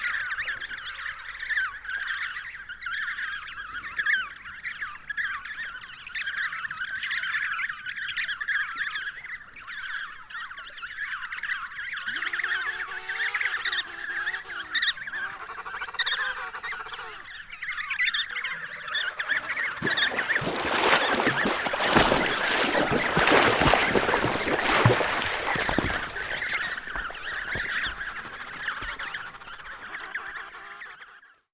• emperor penguin chicks call.wav
emperor_penguin_chicks_call_tM2.wav